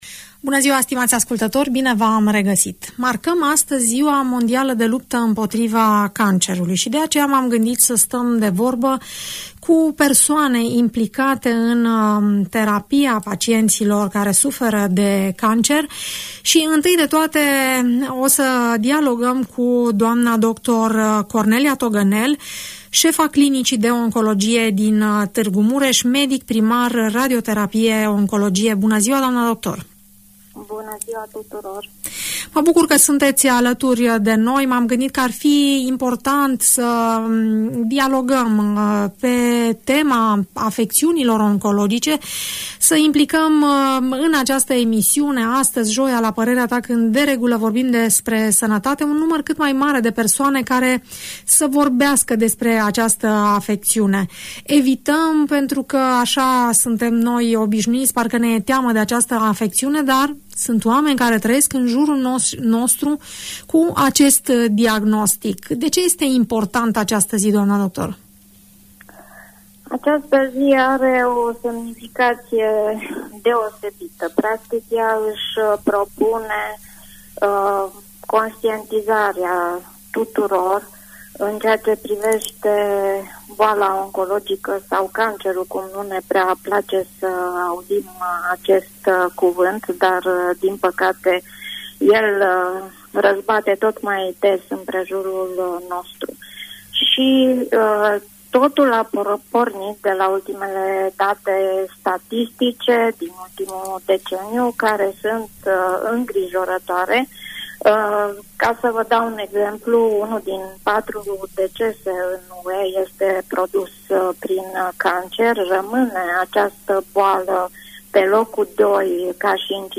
în dialog cu medici, pacienți și psihologi.